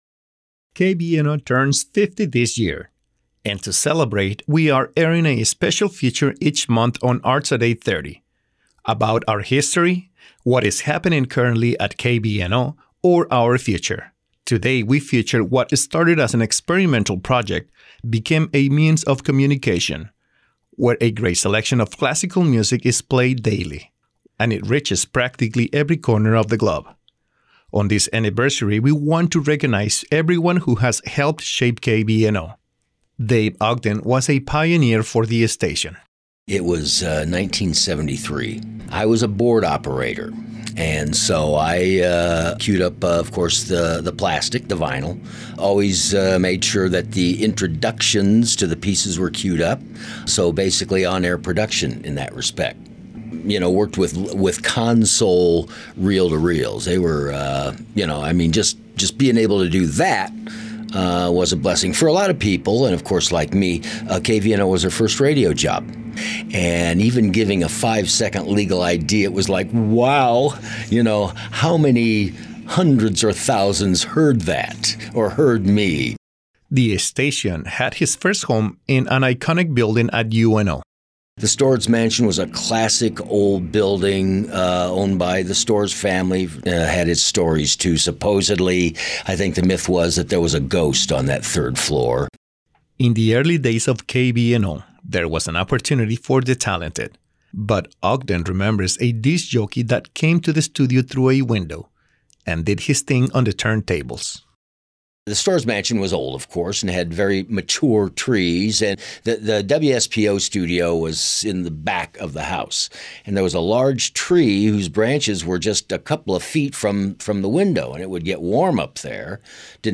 Throughout 2022, KVNO is bringing you a monthly Arts@830 series that celebrates the first 50 years of KVNO and interviews the people who did — and will — make it happen.